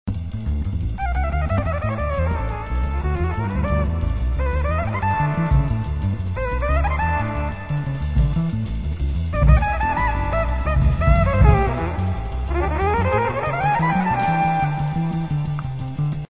The Roland GR-300